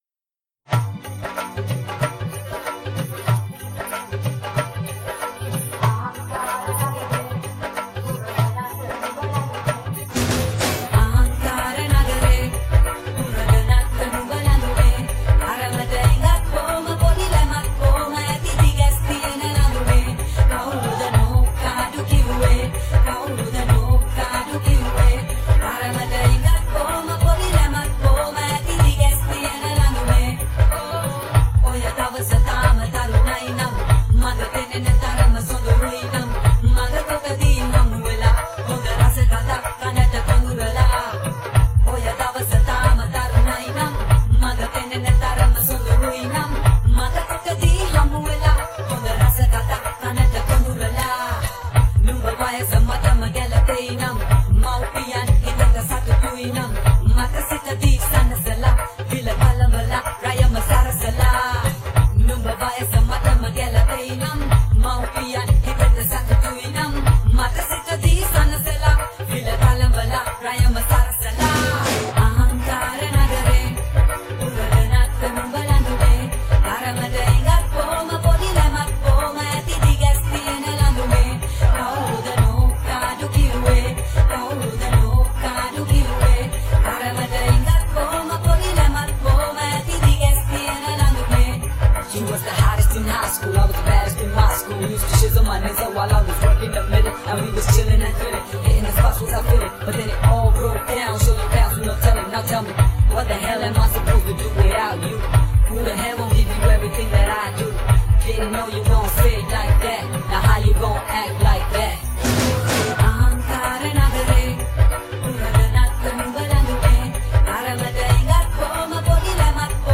Play Karaoke & Sing with Us